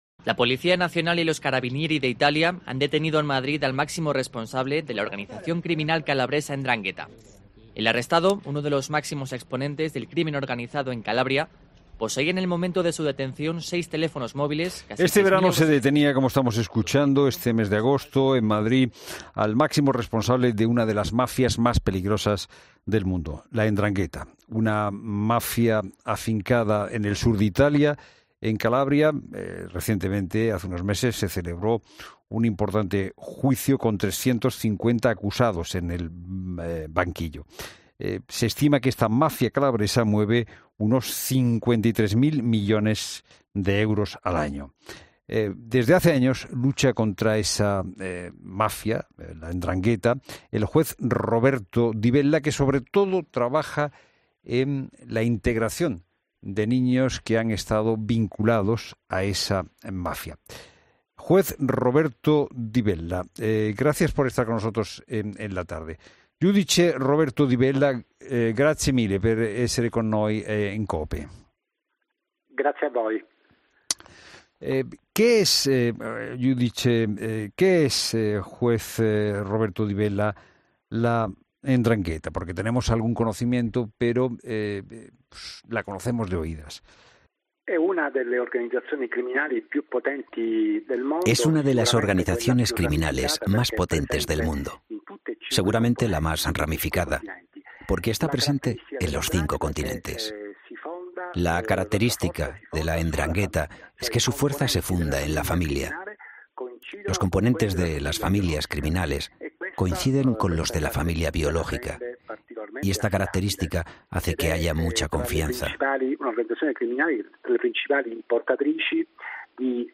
AUDIO: La Tarde de COPE habla con el juez italiano Roberto di Bella de la 'Ndrangheta, una de las organizaciones criminales más sanguinarias de la...